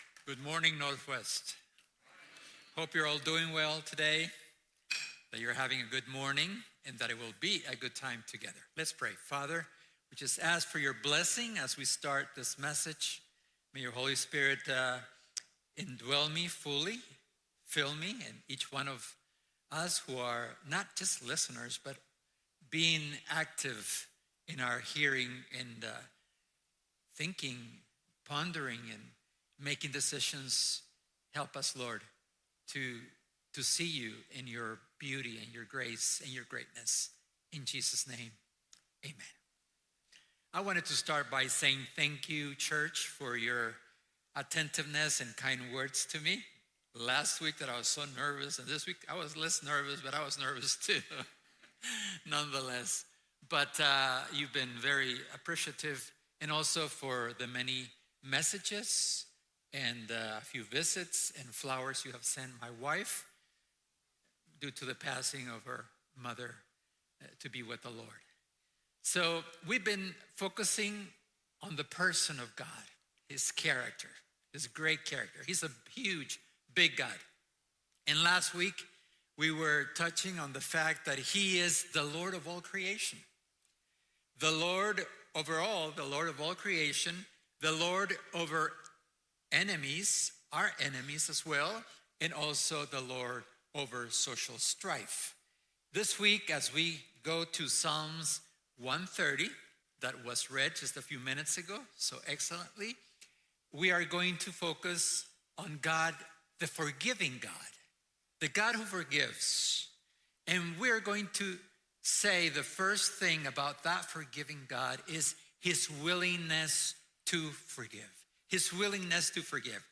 Latest Sermon 04/26/2026